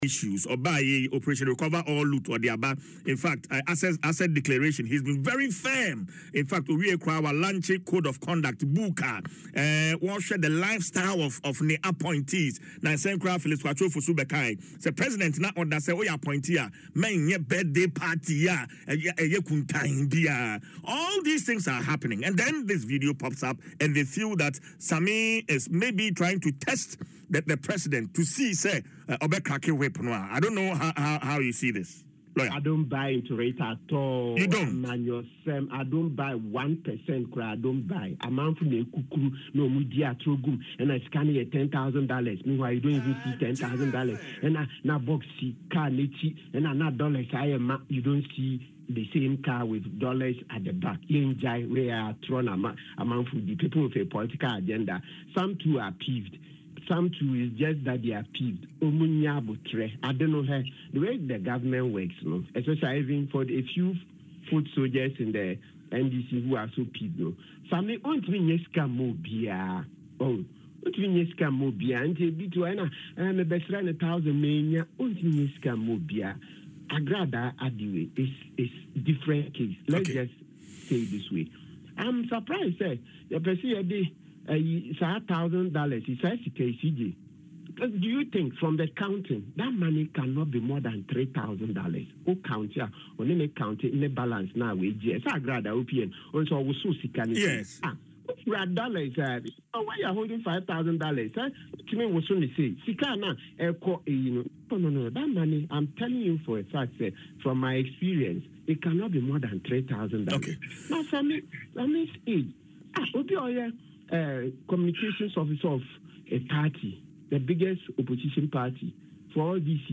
in an interview on Adom FM’s Dwaso Nsem